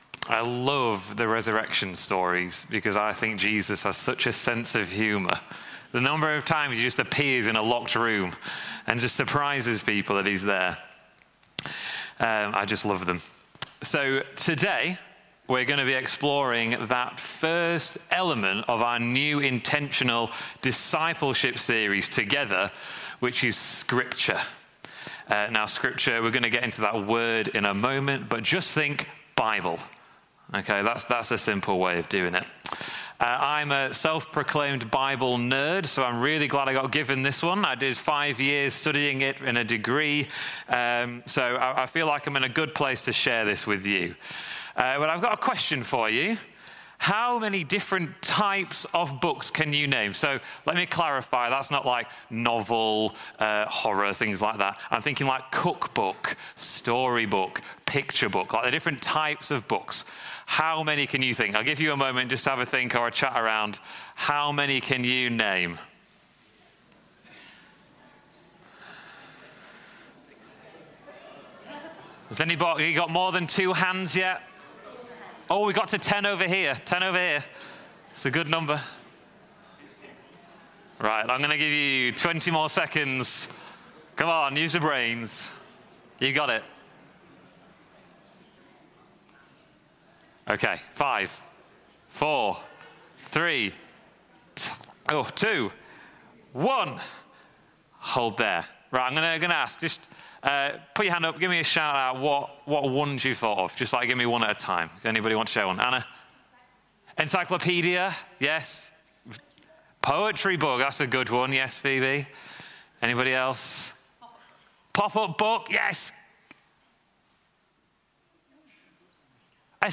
sermon11.1.26ar.mp3